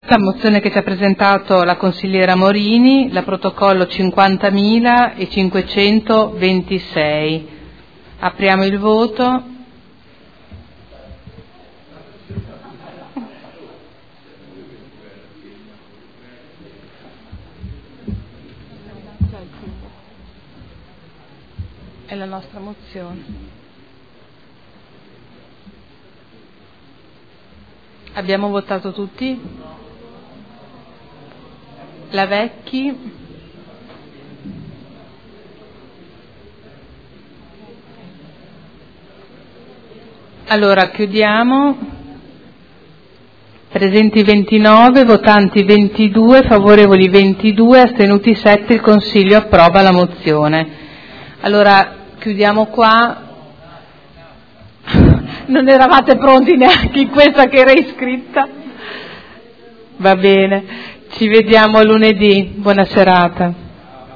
Seduta del 4/02/2013.